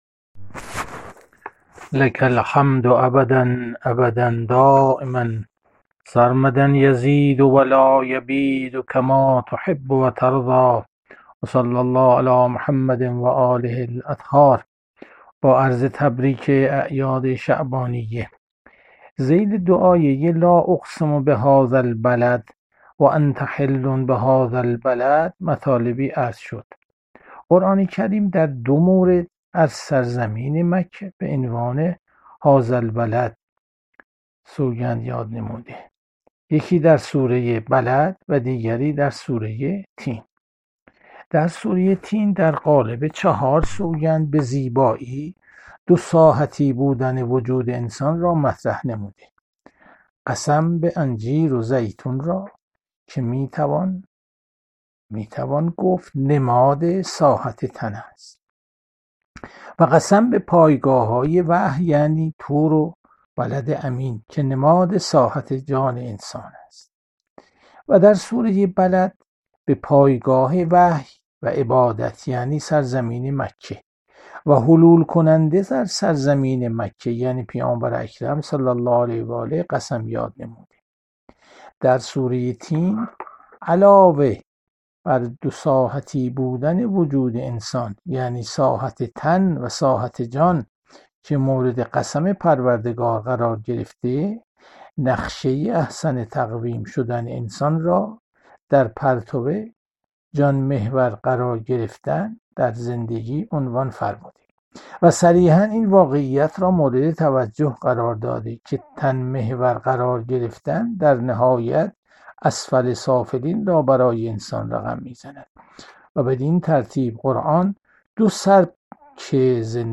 جلسه هفتگی تفسیر قرآن- سوره بلد- جلسه پنجم- 08 اسفند 1401